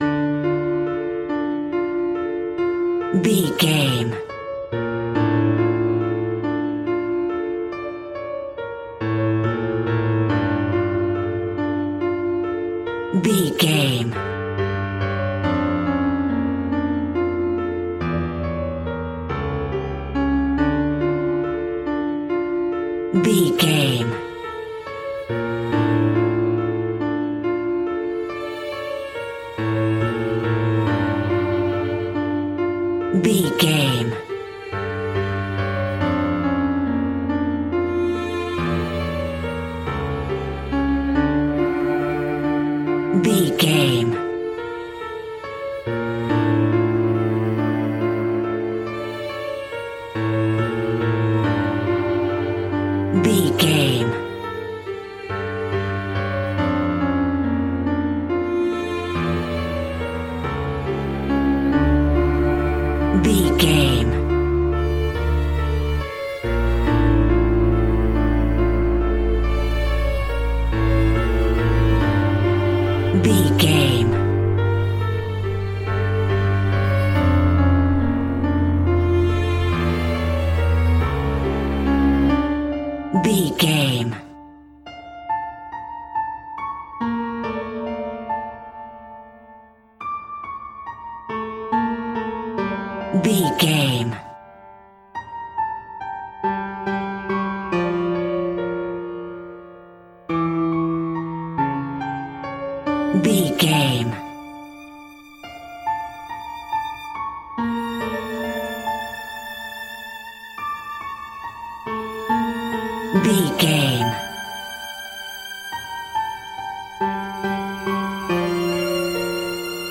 Aeolian/Minor
scary
tension
ominous
dark
haunting
eerie
strings
piano
synth
pads